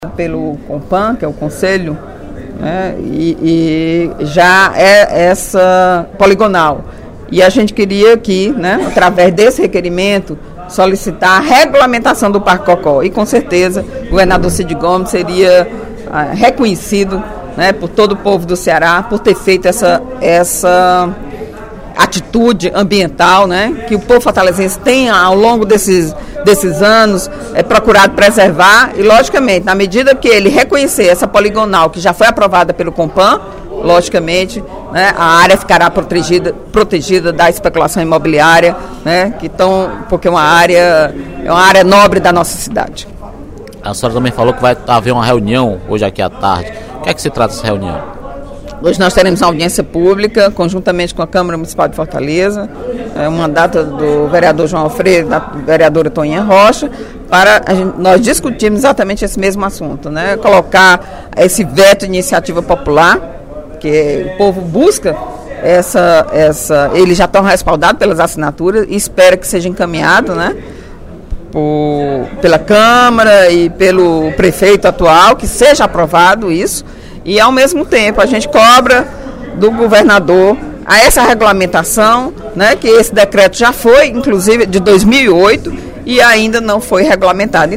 A deputada Eliane Novais (PSB) convidou, durante pronunciamento no primeiro expediente desta quarta-feira (13/03) na Assembleia Legislativa, para uma audiência pública, que será realizada conjuntamente com a Câmara Municipal, para discutir a questão da preservação do Cocó.